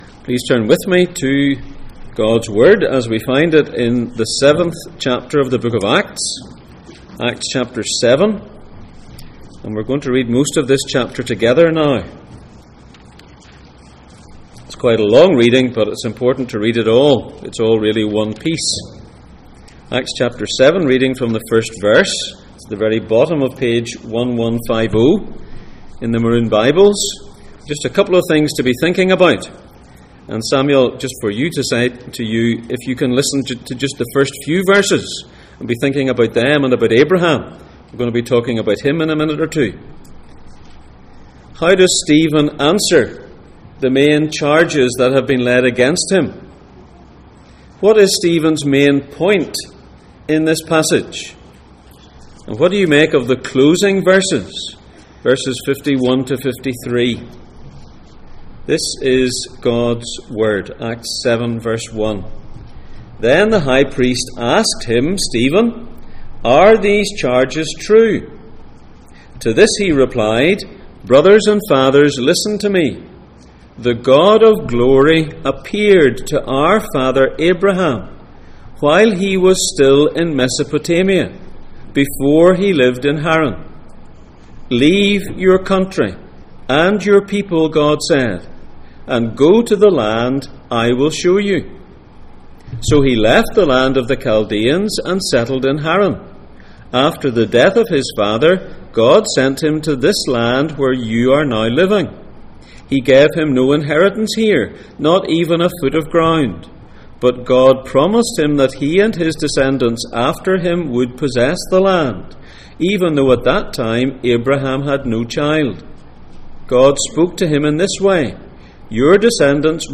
The Acts of the Reigning Jesus Passage: Acts 7:1-53 Service Type: Sunday Morning